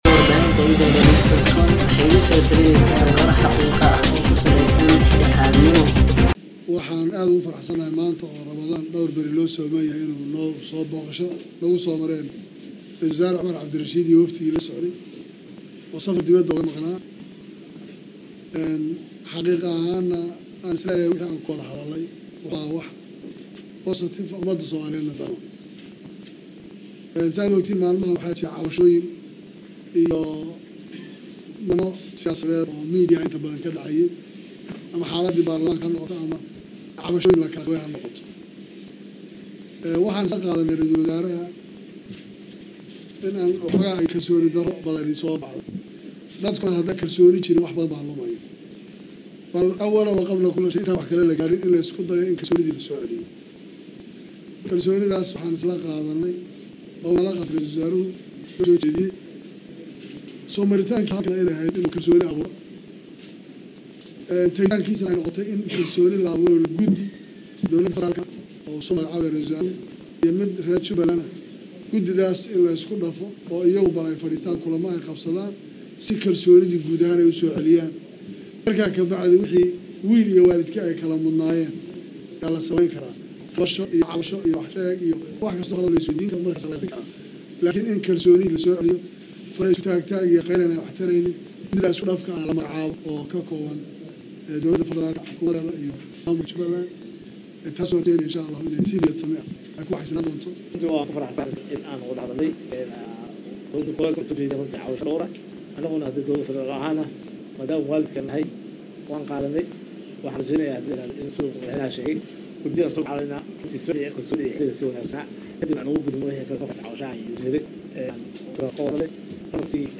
Raysalwasaaraha iyo wefdigiisa iyo maamulka Jubba waxaa garoonka diyaaradaha ee Kismaayo uga furmey wada hadal albaabadu u xiran yhiin, shirkaas kadib waxaaa si wada jir ah saxaafadda ula hadley Raysalwasaaraha Soomaaliya Cumar Cabdirashiid iyo Madaxweynaha Jubba Axmed Maxamed Islaan.